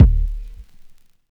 Kick (10).wav